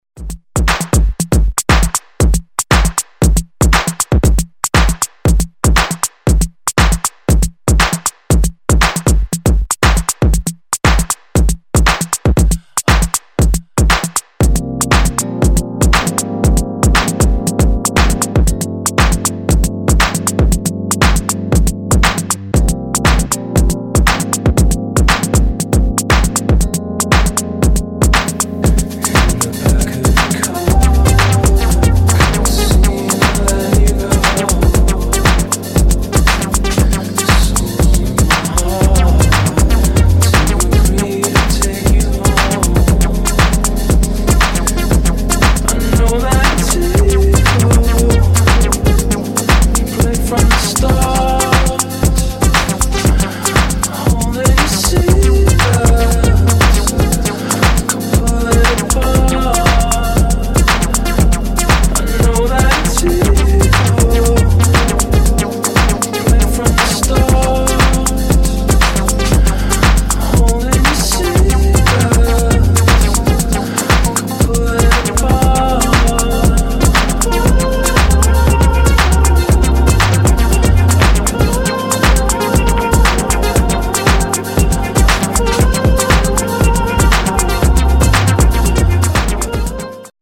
[ TECH HOUSE / BASS ]